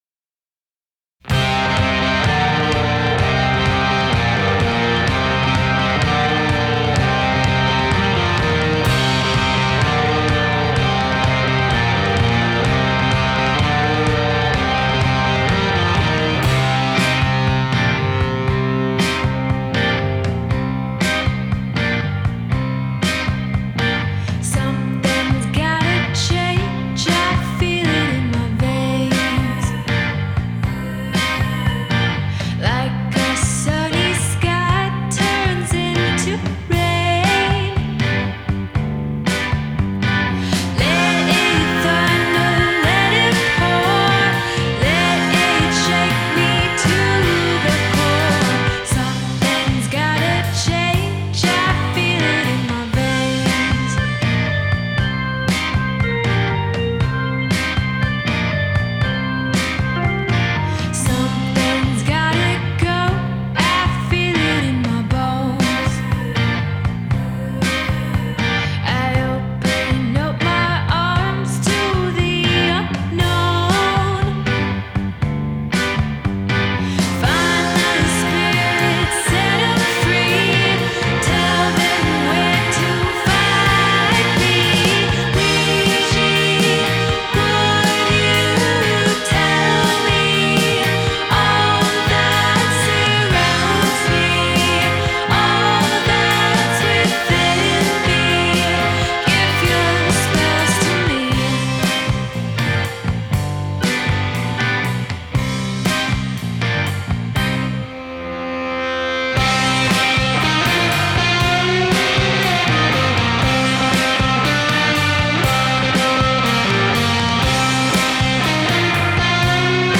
Genre: indie pop, dream pop, indie rock